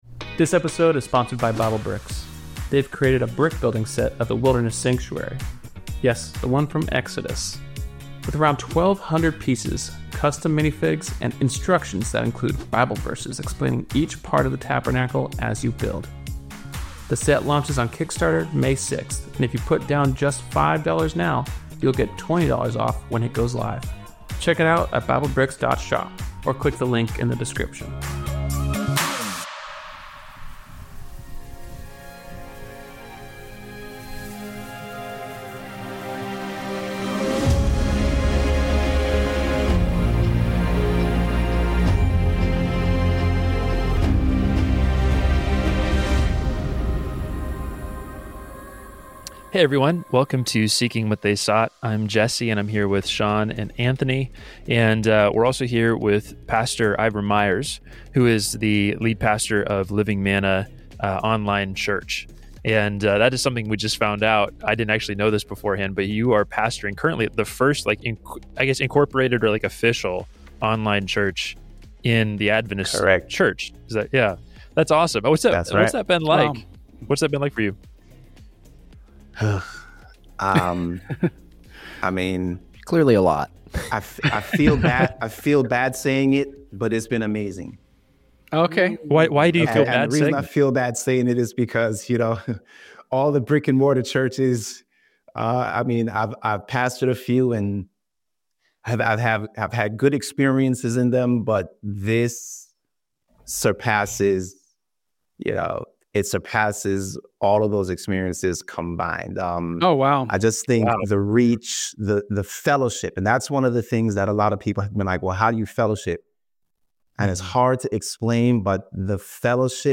In episode 8, we sit down for a conversation